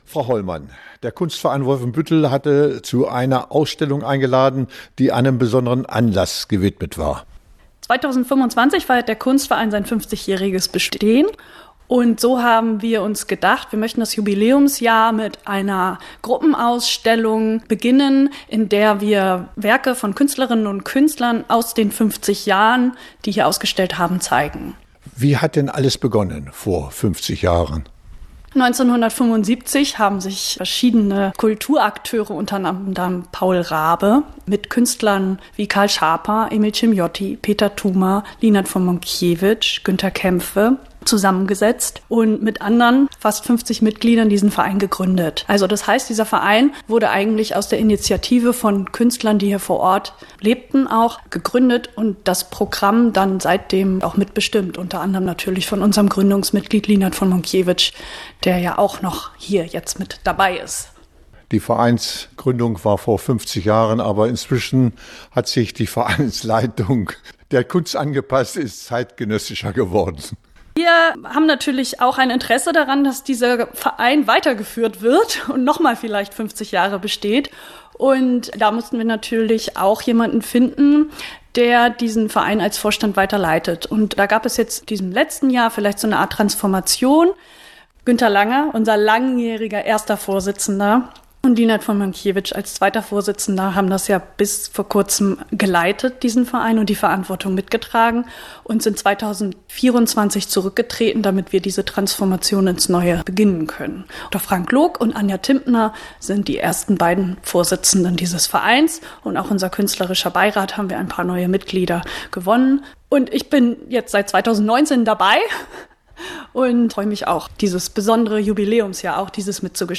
Interview-50-Jahre-Kunstverein-WF_kv.mp3